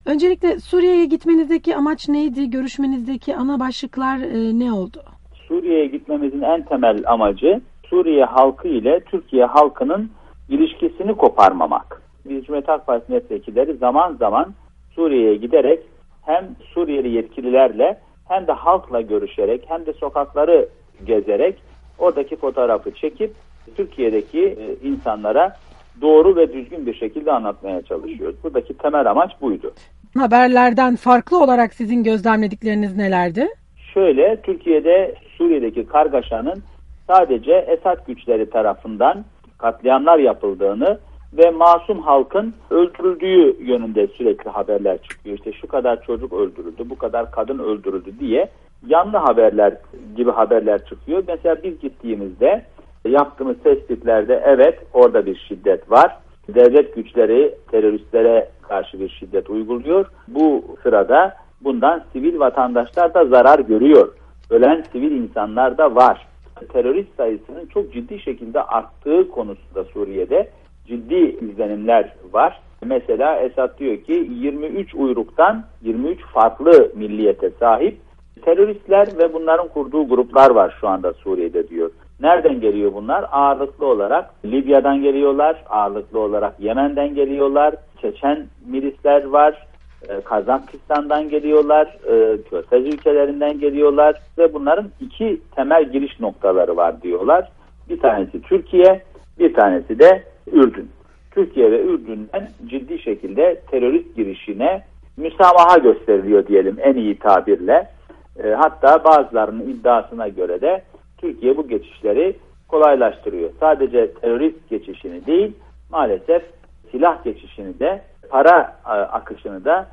Aytuğ Atınç'la röportaj